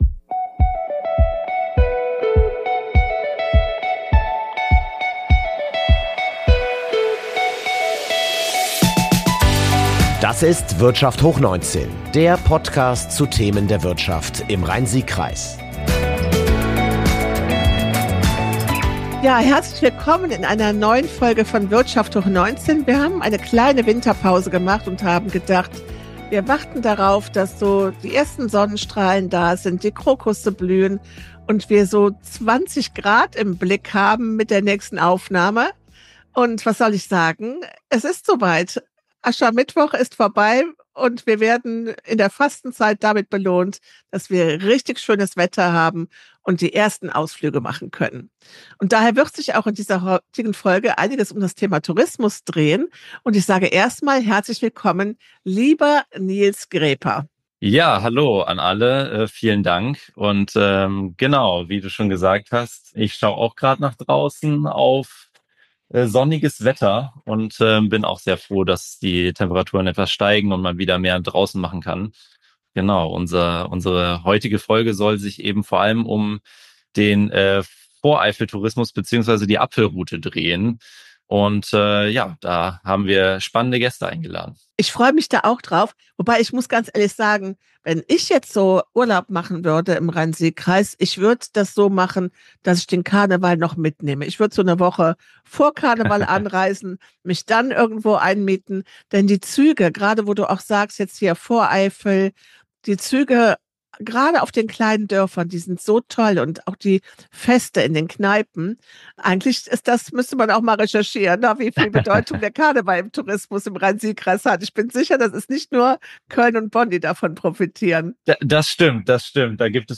Im Gespräch stellen die Gäste die Region, ihre landwirtschaftlichen Betriebe, Produkte und Aktivitäten vor. Die Zuhörer lernen Erstaunliches kennen: dass Frostschutz durch Berieselung funktioniert und wie ein Rechtstreit mit Apple zum Wirtschaftsmotor werden kann.
Diese Podcastfolge ist wie eine Audio-Tour durch das wirtschaftlich und touristisch aktive, attraktive Anbaugebiet nahe der Bundesstadt Bonn.